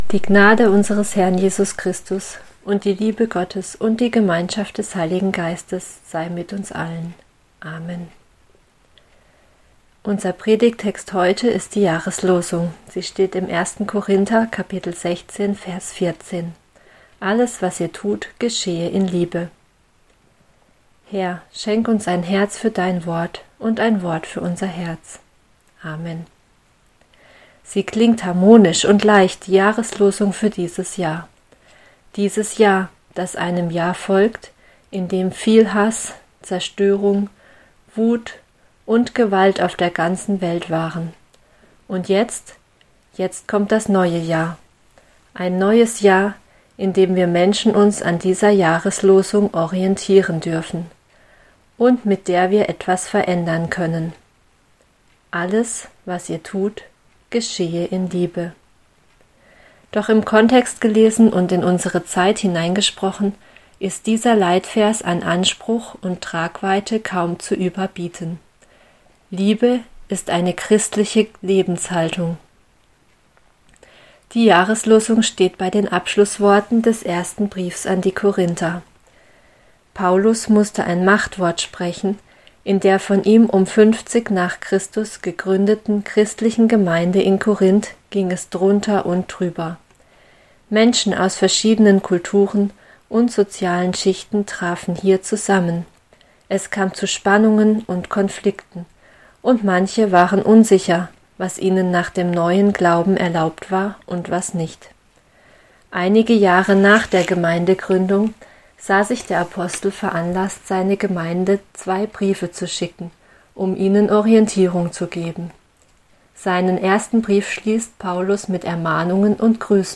Predigt zum Anhören | Predigt zum Nachlesen | Lied zur Jahreslosung